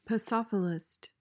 PRONUNCIATION:
(puh-SOF-uh-list)